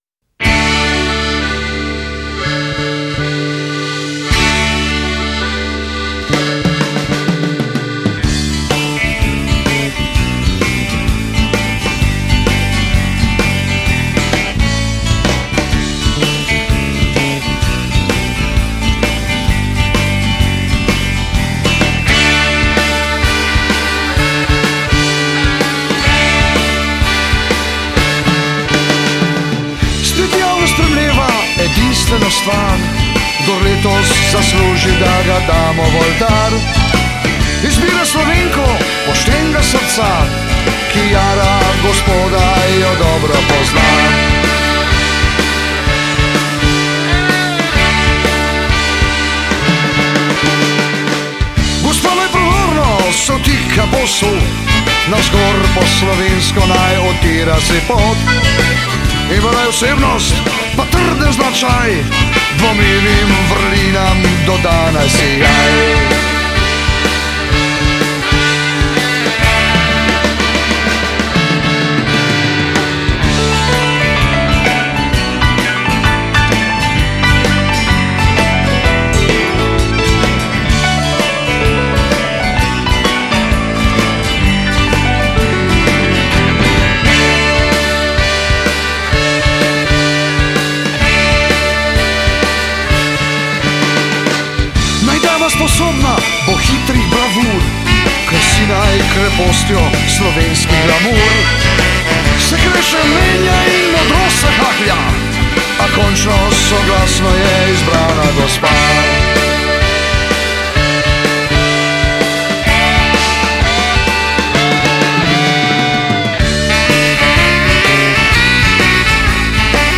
vokal, kitara
harmonika, klaviature
bas kitara
bobni
saksofon
pozavna
trobenta